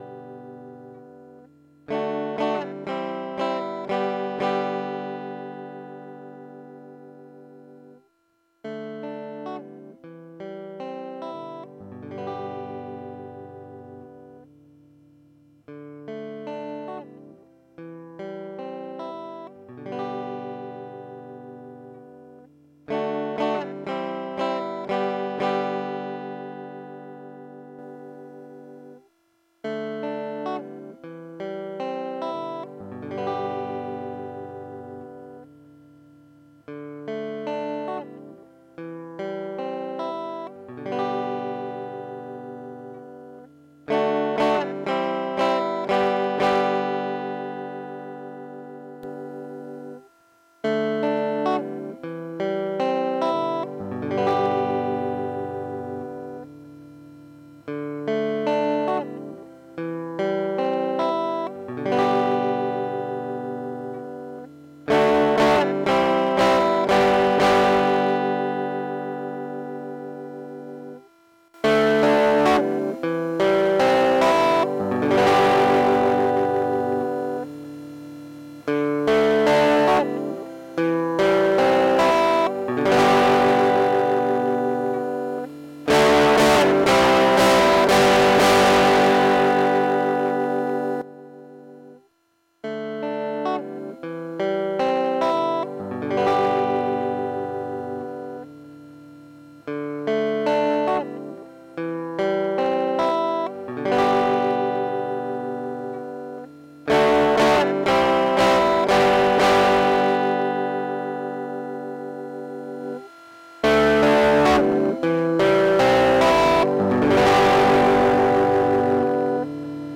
Eingespielt mit einer Epiphone Les Paul Standard Pro (Bridge Pickup im Single Coil), Toneport UX2 und Ableton Live. Komplett ohne Effekte, nur der mylk[386]. Bitte um Entschuldigung für das Rauschen, der mylk[386] ist nicht wirklich gut geschirmt. Sogar das Blinken der LED am Looper hört man...